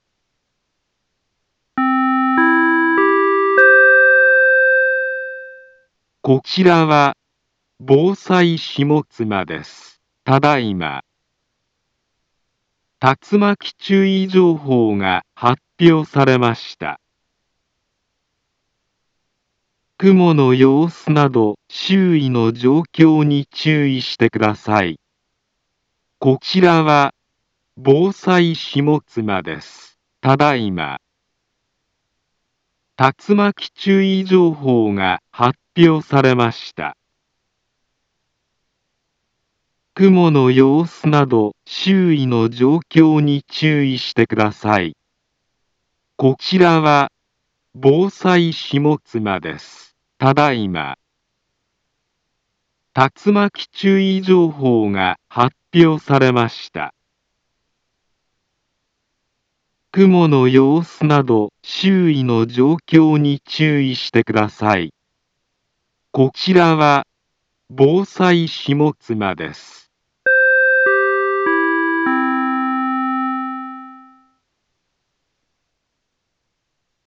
Back Home Ｊアラート情報 音声放送 再生 災害情報 カテゴリ：J-ALERT 登録日時：2021-07-11 17:05:25 インフォメーション：茨城県北部、南部は、竜巻などの激しい突風が発生しやすい気象状況になっています。